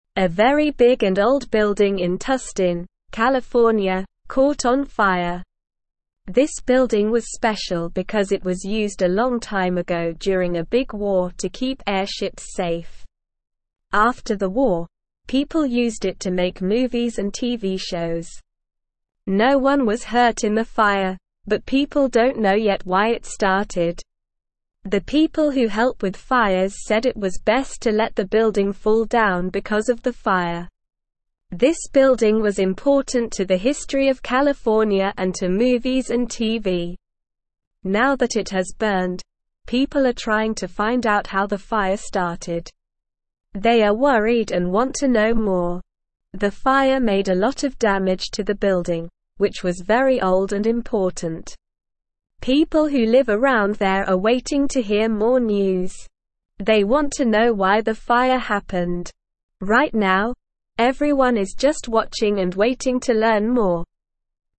Slow
English-Newsroom-Lower-Intermediate-SLOW-Reading-Big-Old-Building-in-California-Catches-Fire.mp3